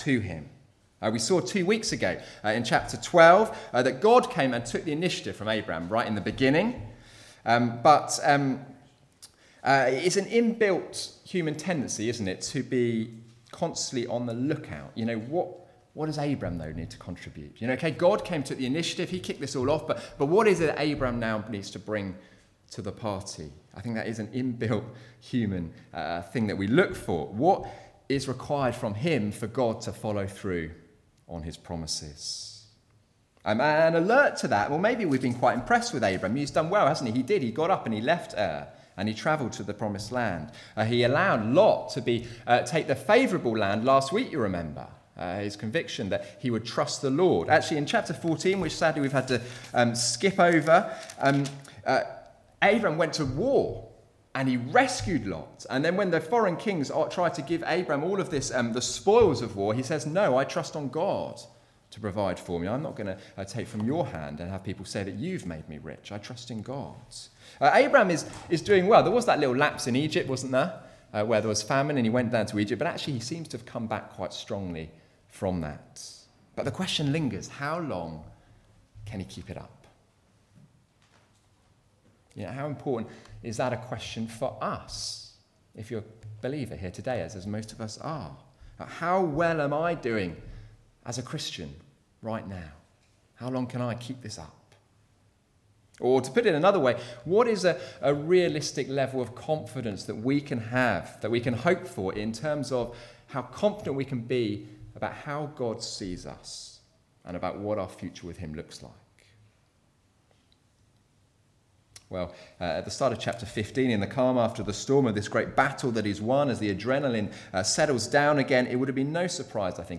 30th-Jan-Sermon.mp3